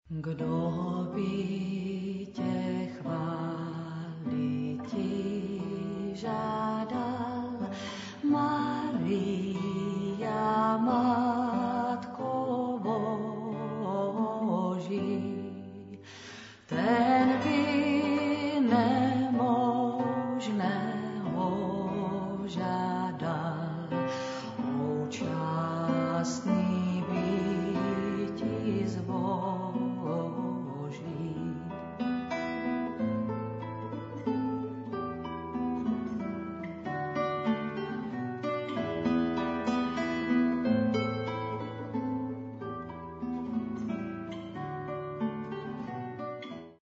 kytara a zpěv